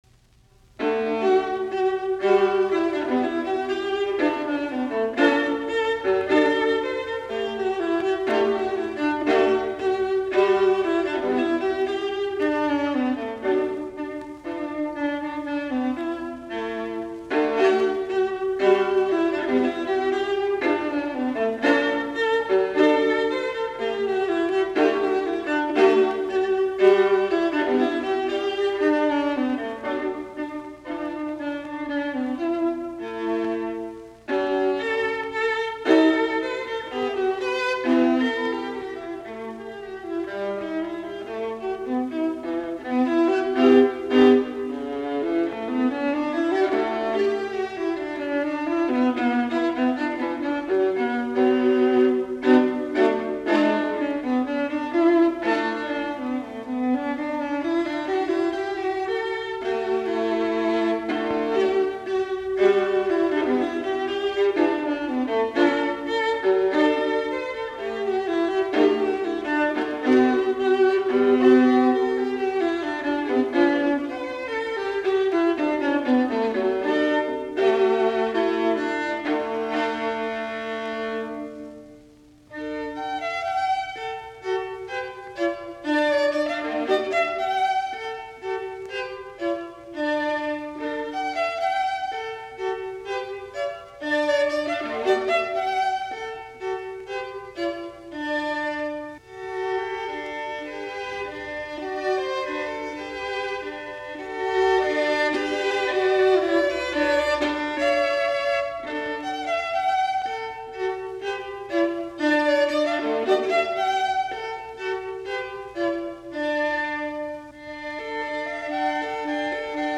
alttoviulu
Soitinnus: Alttoviulu.